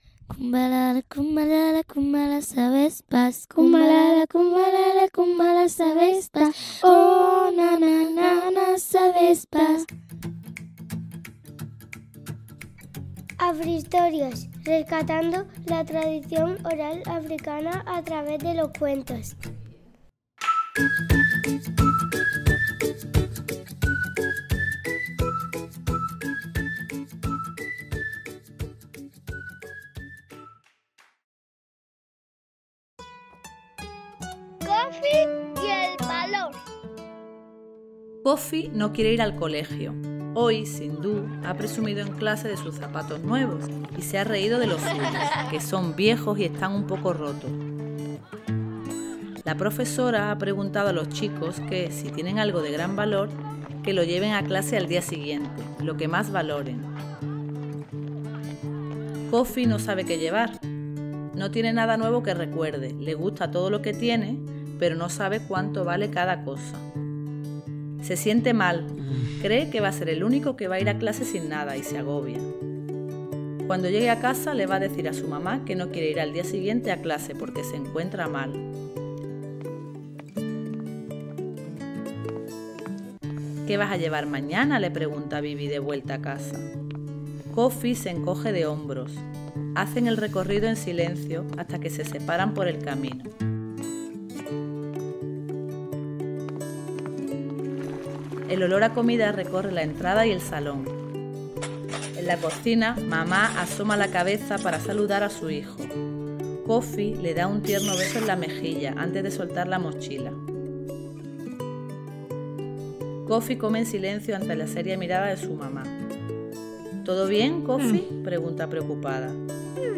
Este cuento ofrece una lección conmovedora sobre lo que realmente importa en la vida. Un cuento original